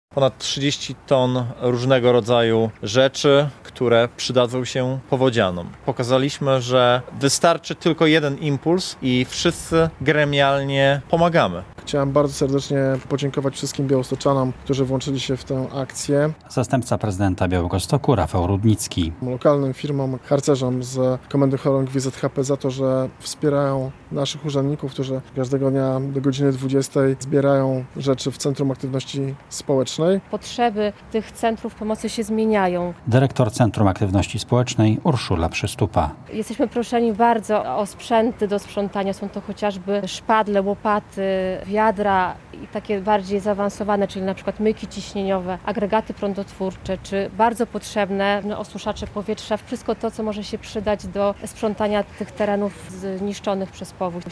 Z Białegostoku wyjechał czwarty transport z darami dla powodzian - relacja